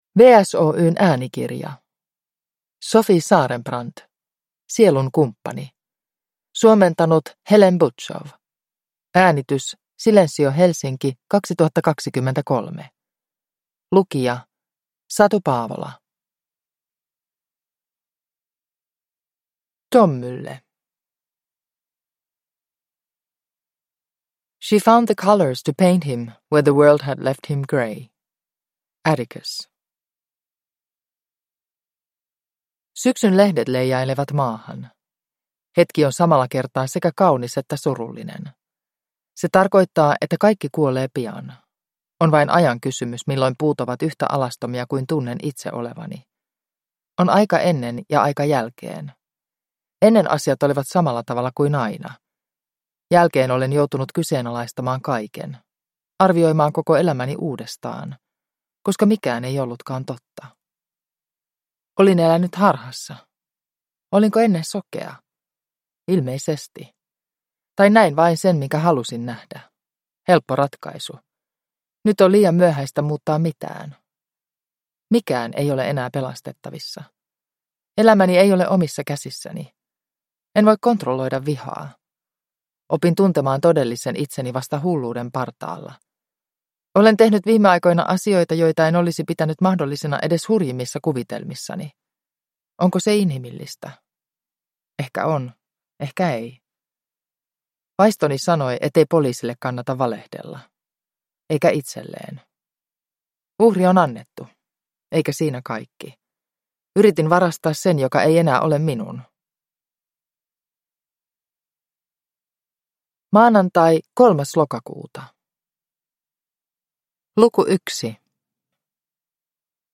Sielunkumppani – Ljudbok – Laddas ner